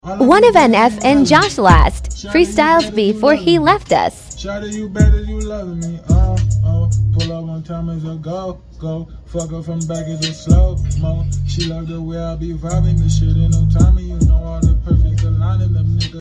trini rapper freestyle